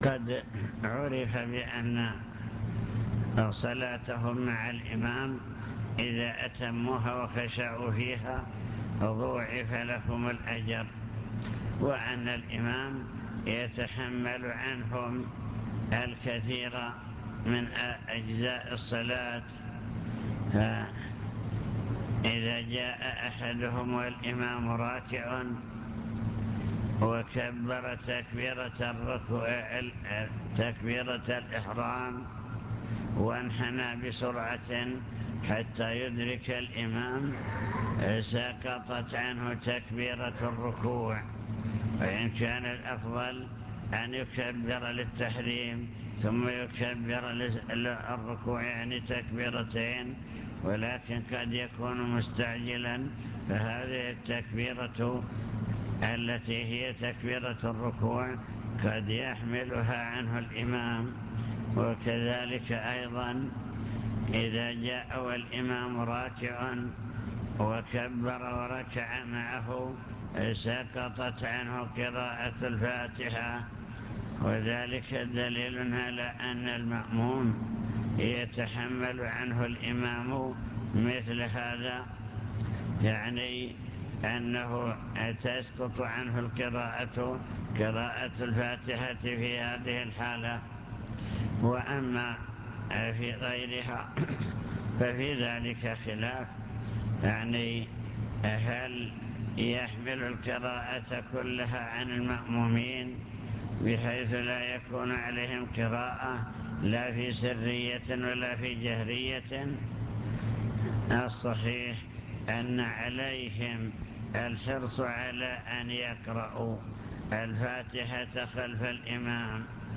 المكتبة الصوتية  تسجيلات - محاضرات ودروس  محاضرة في بدر بعنوان: وصايا عامة